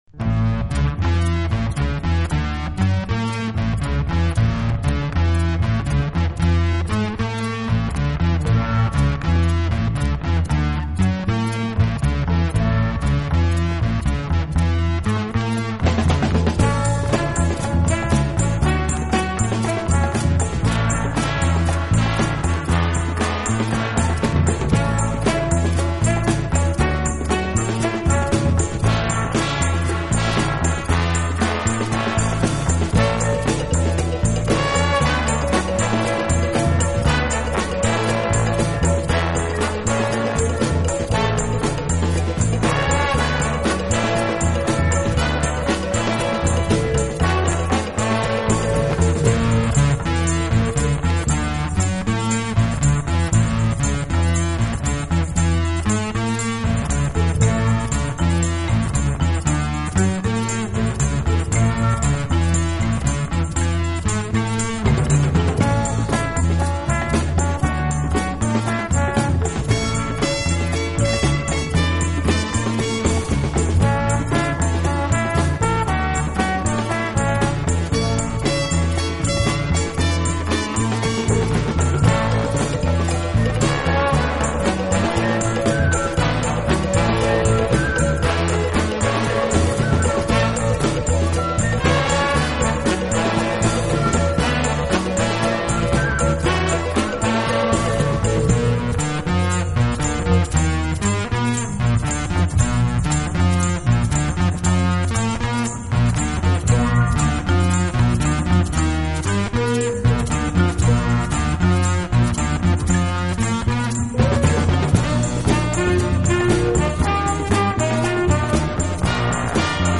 【轻音乐专辑】
以擅长演奏拉丁美洲音乐而著称。演奏轻柔优美，特別是打击
乐器的演奏，具有拉美音乐独特的韵味。